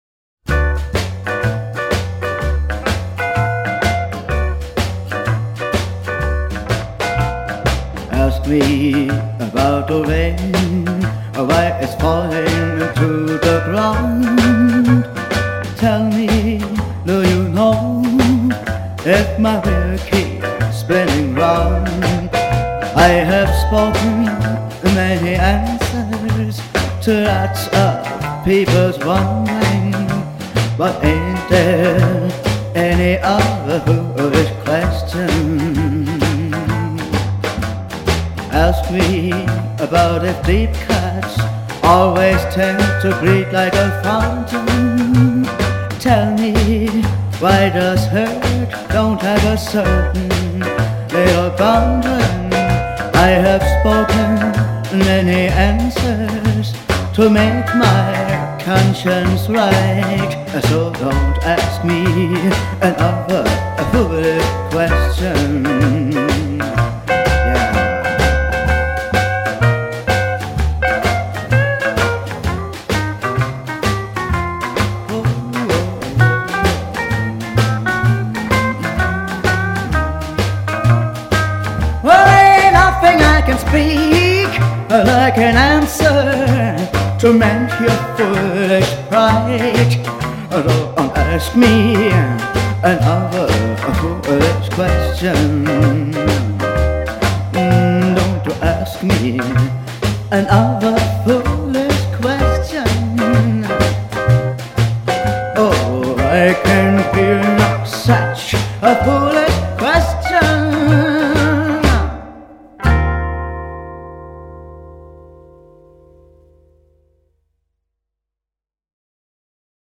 Uptempo Version